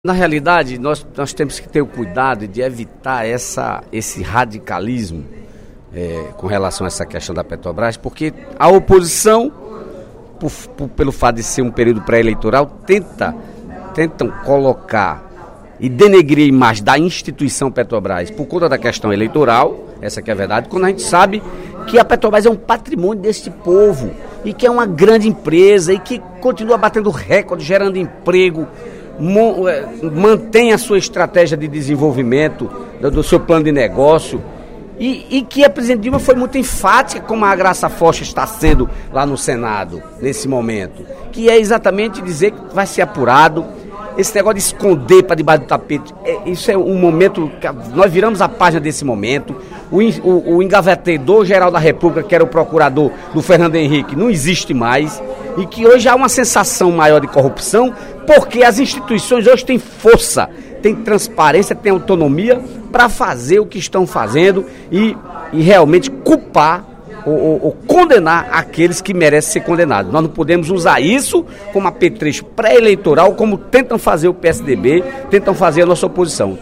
No primeiro expediente da sessão plenária da Assembleia Legislativa desta terça-feira (15/04), o deputado Dedé Teixeira (PT) defendeu a Petrobras.